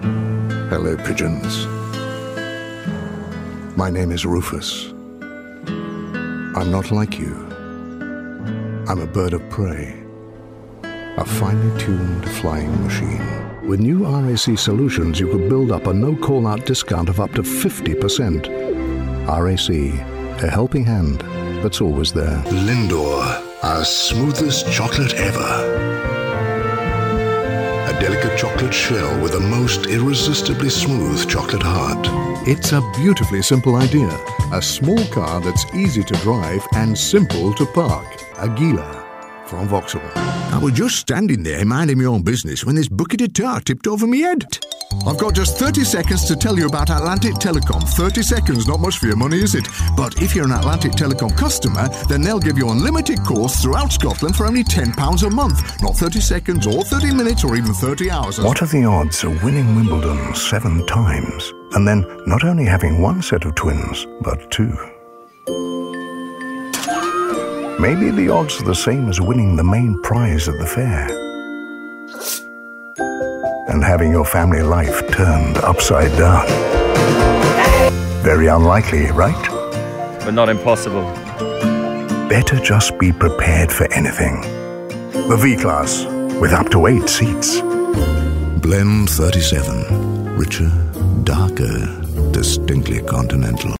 Commercial Showreel
RP ('Received Pronunciation'), Straight